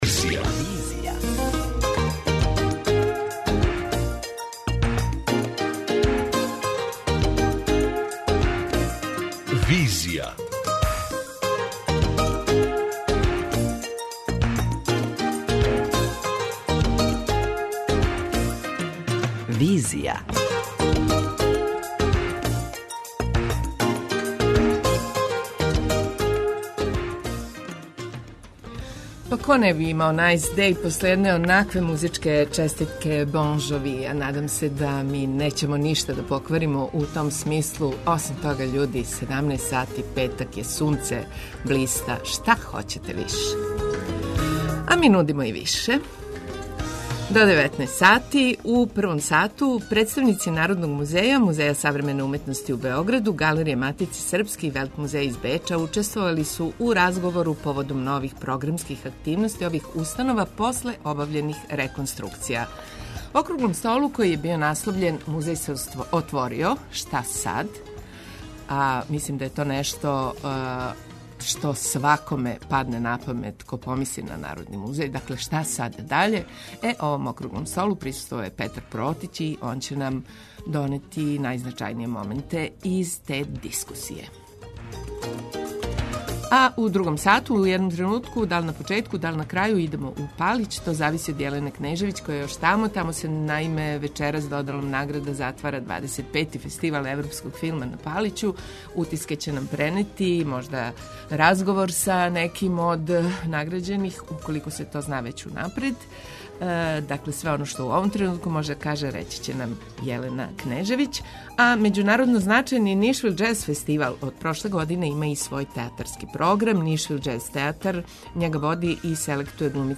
преузми : 55.85 MB Визија Autor: Београд 202 Социо-културолошки магазин, који прати савремене друштвене феномене.